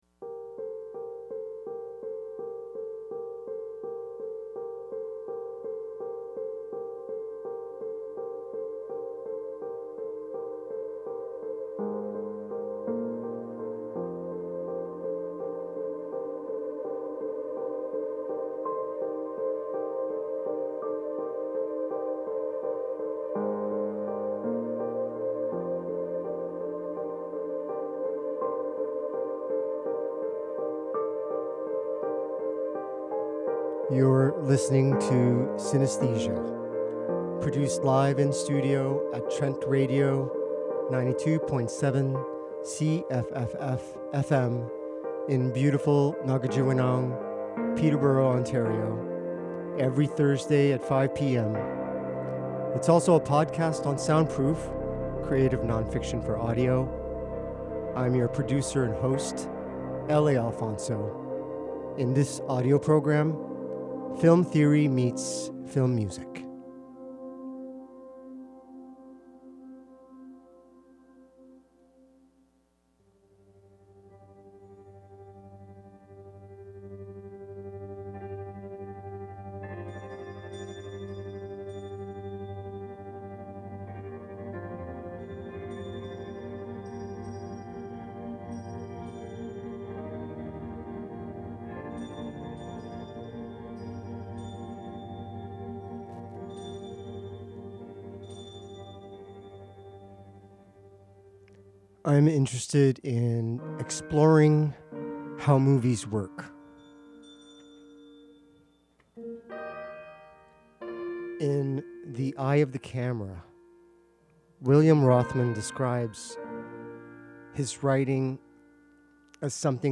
Cinesthesia 10 Original Broadcast Radio Playlist March 21, 2024 1.